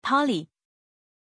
Pronunciation of Polly
pronunciation-polly-zh.mp3